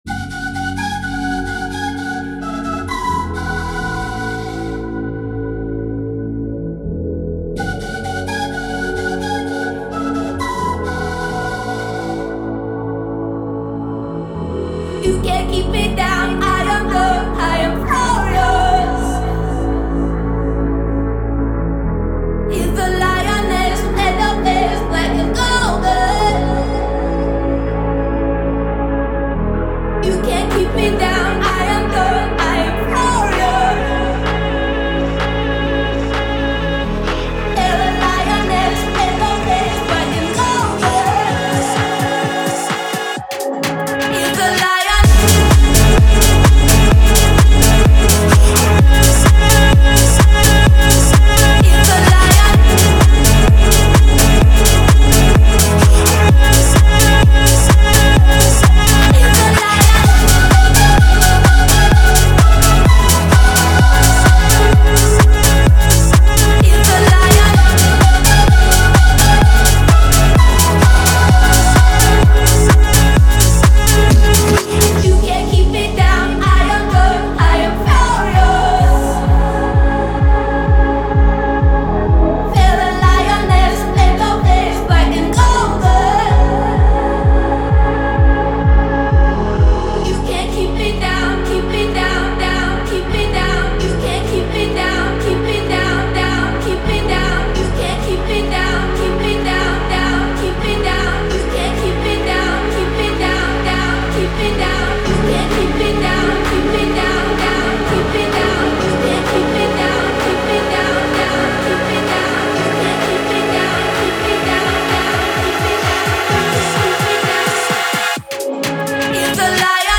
• Жанр: House, Dance